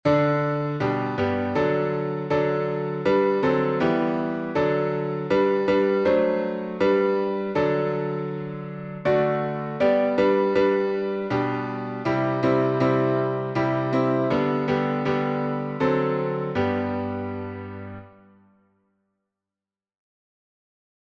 O for a Heart to Praise My God — five stanzas in Three Two.
Arranged by Lowell Mason (1792-1872).Key signature: G major (1 sharp)Time signature: 3/4Meter: 8.6.8.6.(C.M.)Public Domain1.